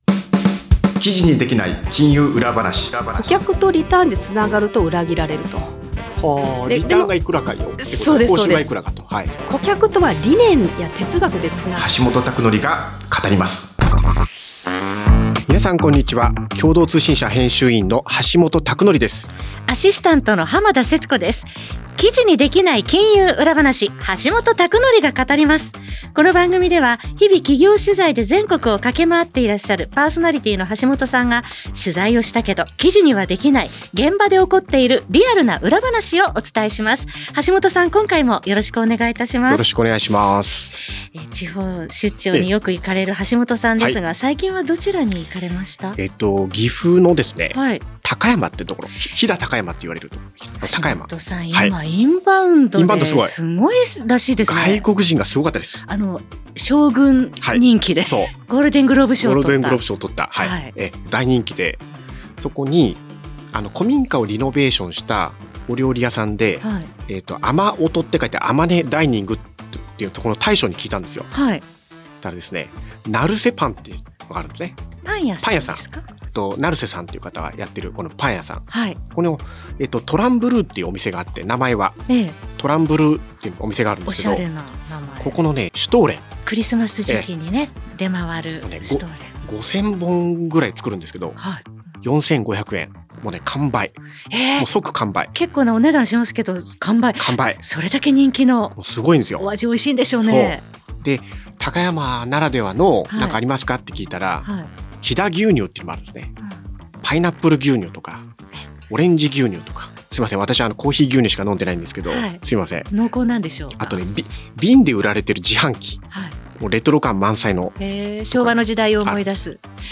また、金融・行政・地方経済・経営など、ビジネス改革の最前線で活躍するプロフェッショナルをゲストに呼んで、ぶっちゃけトークを展開。